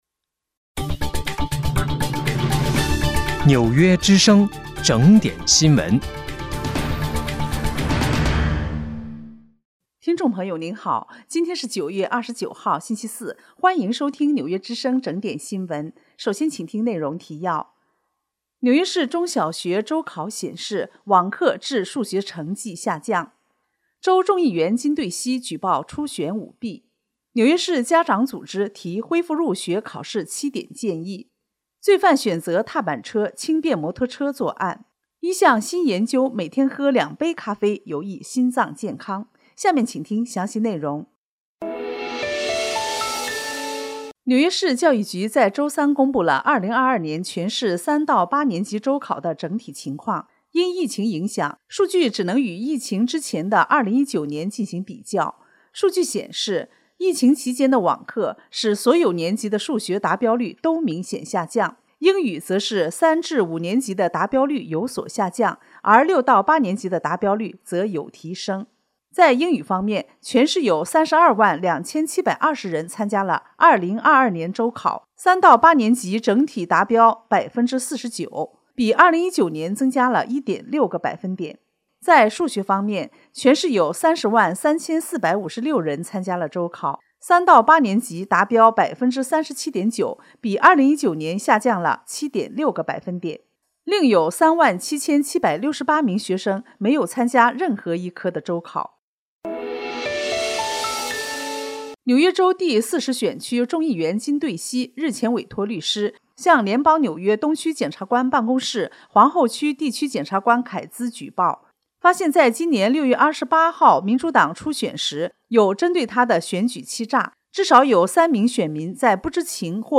9月29号(星期四)纽约整点新闻